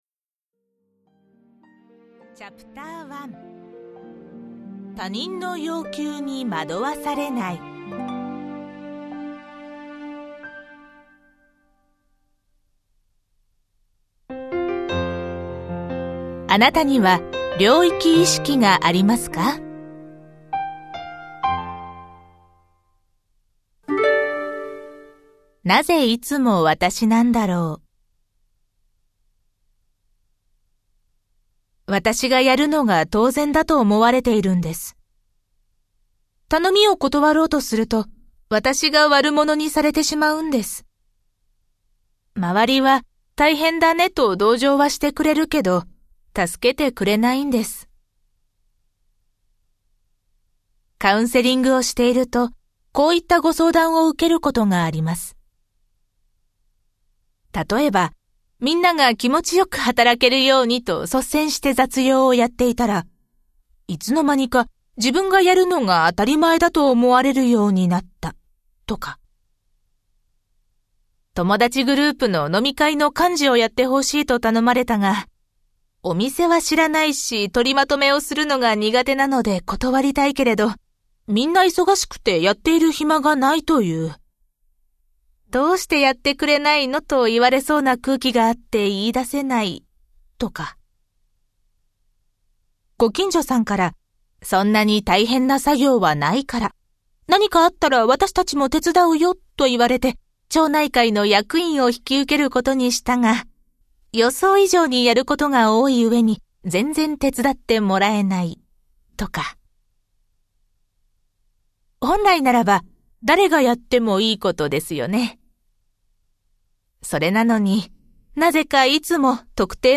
[オーディオブック] ゆずらない力